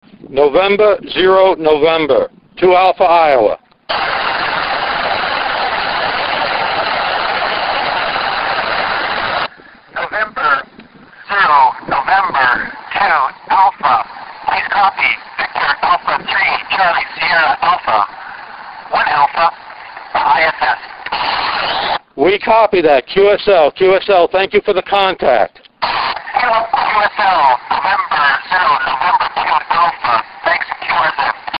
VHF
VA3CSA Field Day - over North America